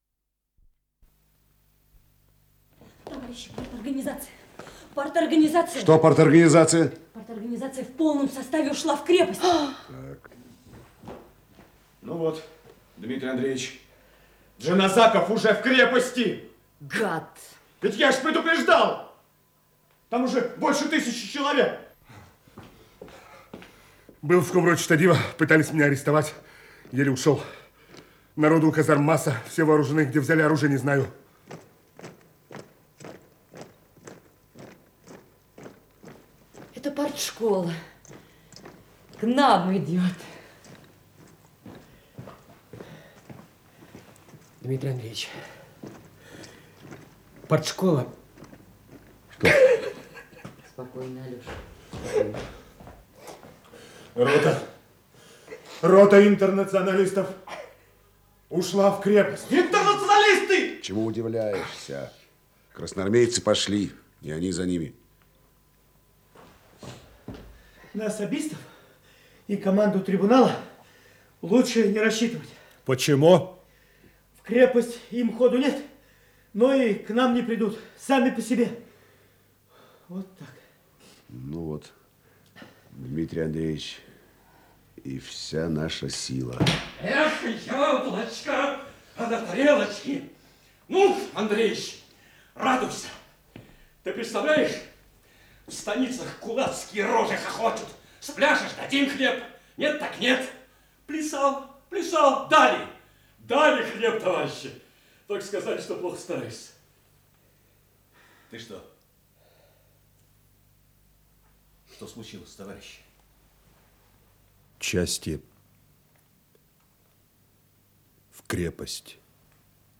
Исполнитель: Артисты МХАТ СССР им. Горького
Радиокомпозиция спектакля